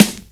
• 2000s Prominent Rap Snare One Shot G# Key 144.wav
Royality free snare sample tuned to the G# note. Loudest frequency: 2350Hz